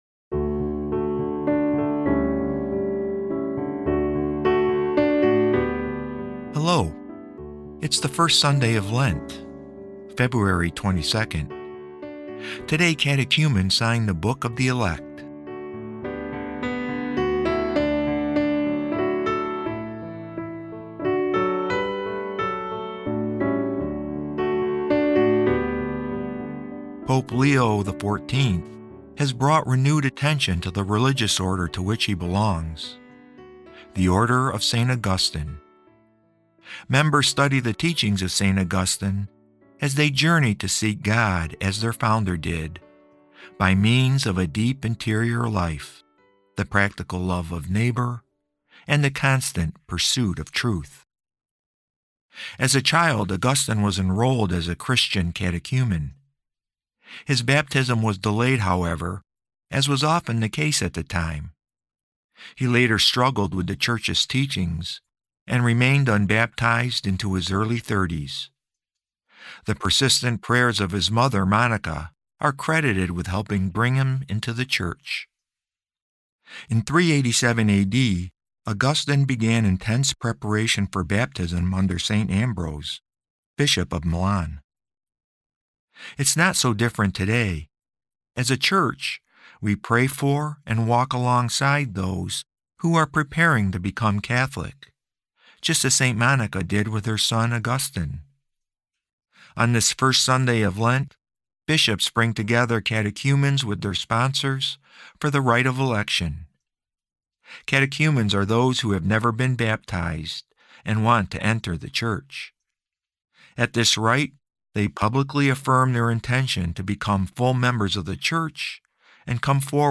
Today's episode of Sundays with Bishop Ken is a reading from The Little Black Book: Lent 2026.